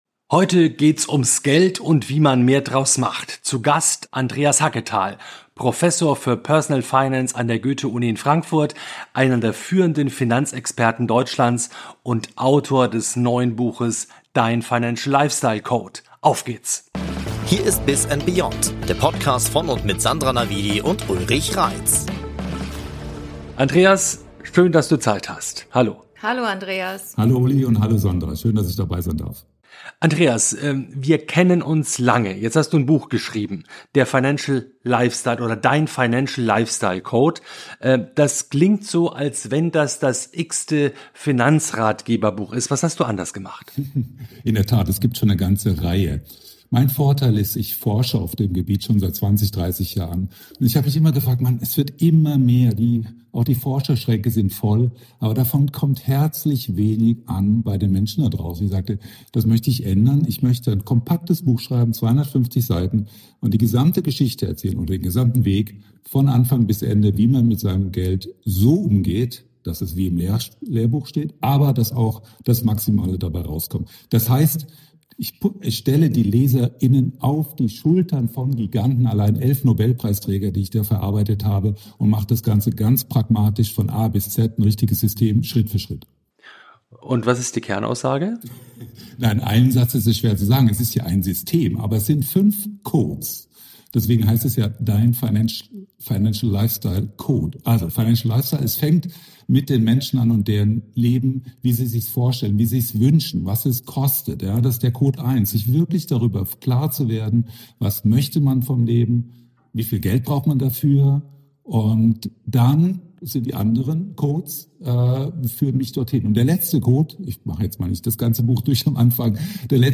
Ein Gespräch, das mit Mythen aufräumt, Gewissheiten erschüttert und zeigt, wie man richtig spart.